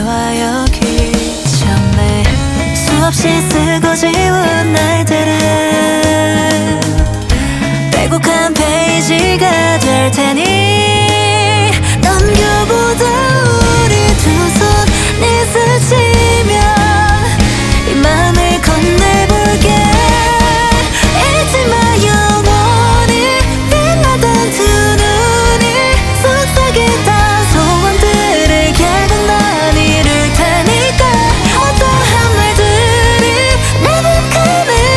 Korean Rock
2025-08-12 Жанр: Рок Длительность